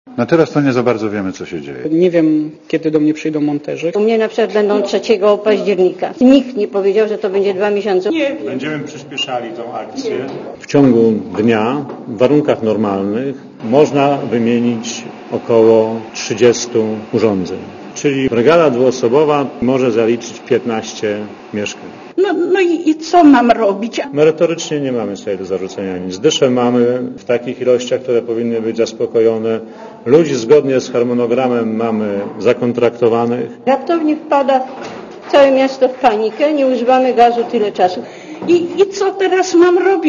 Posłuchaj mieszkańców Słupska